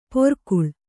♪ porkuḷ